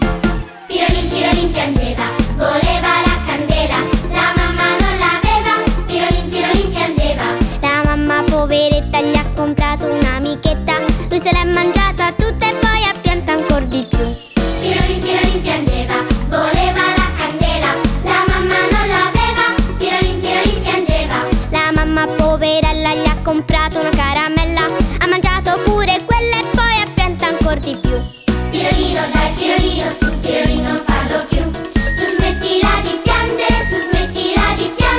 # Children's Music